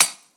darkwatch/client/public/dice/sounds/surfaces/surface_metal7.mp3 at 0a32b261e0304ef4a0dabc626702e9d83e4a4a64
surface_metal7.mp3